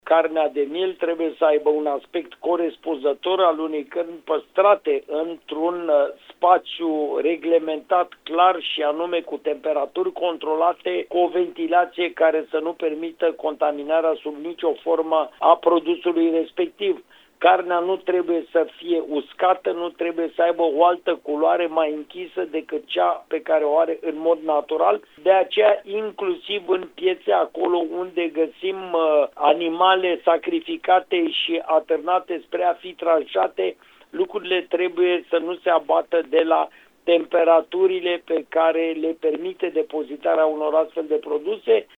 Unul dintre ele este aspectul, spune comisarul șef adjunct al Comisariatului Județean pentru Protecția Consumatorilor Constanța, Horia Constantinescu: